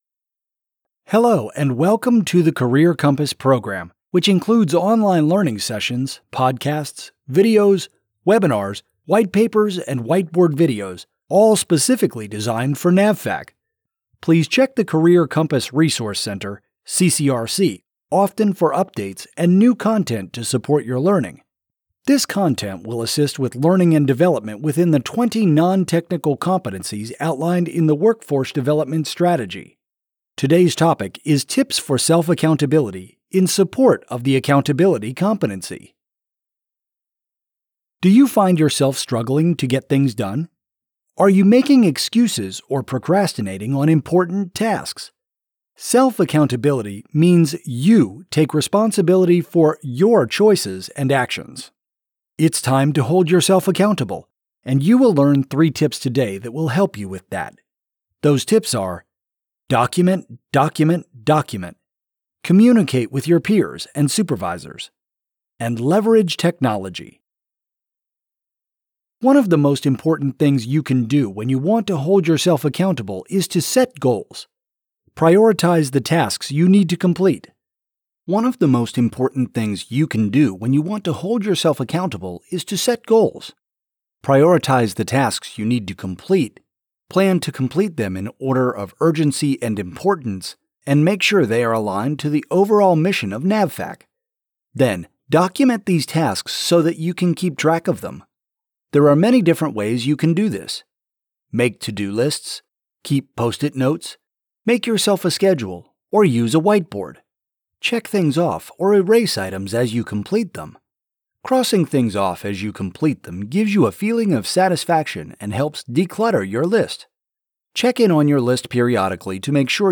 These 5 – 10 minute podcasts include facilitated discussions on select competency-related topics. They contain tips and techniques listeners can learn and quickly apply on-the-job.